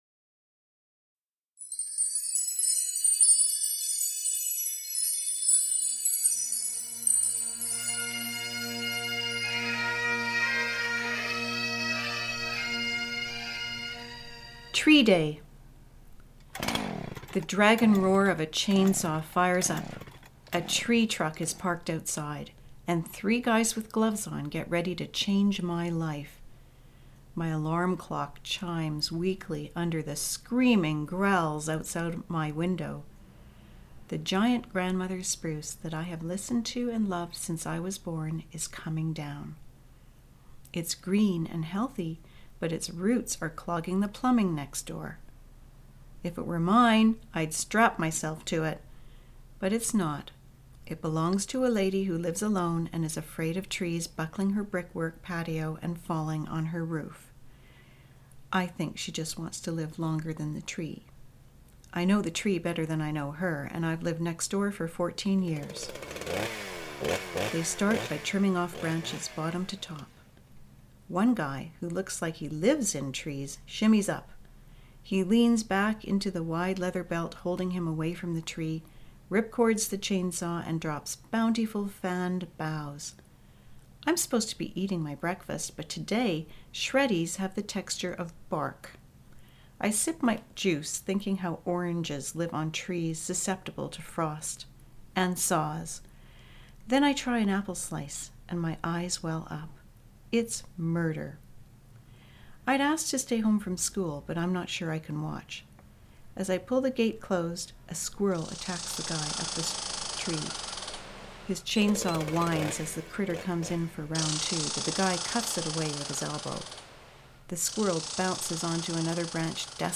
Tree_Day_Version2 Sound design by Chronopia Communications.